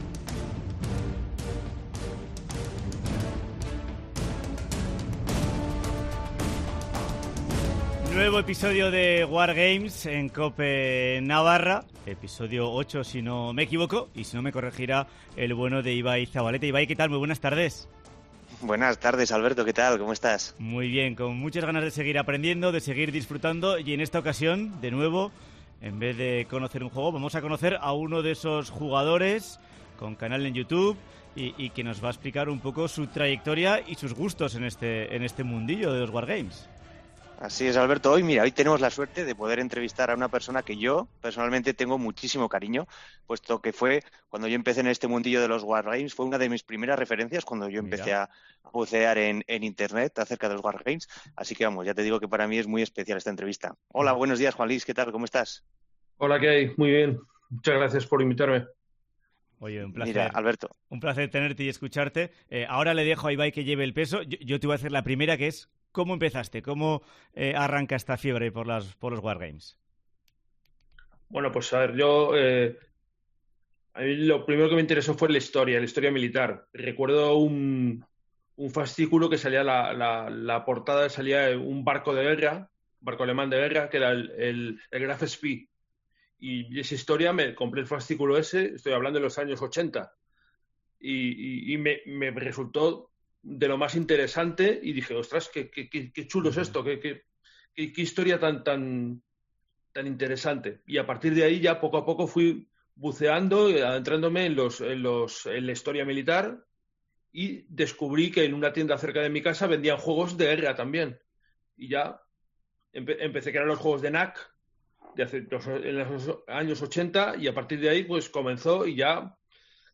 VÍDEO DE LA ENTREVISTA